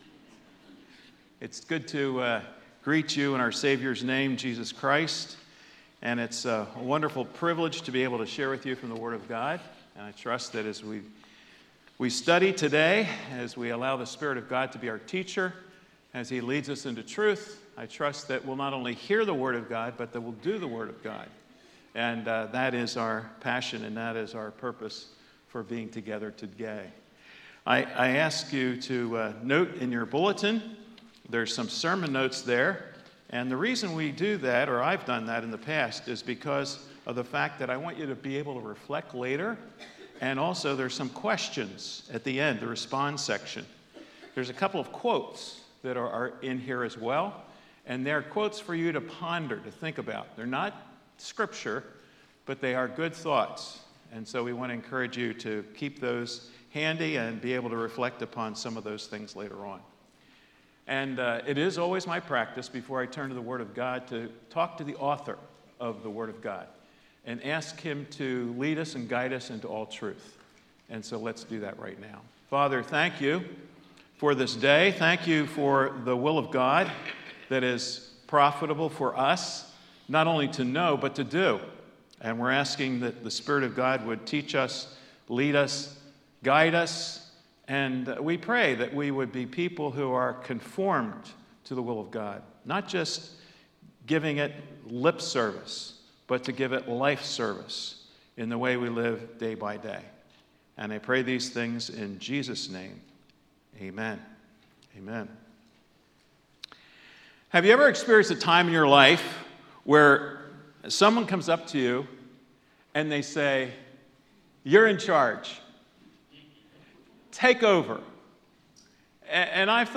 Sermons | Calvary Monument Bible Church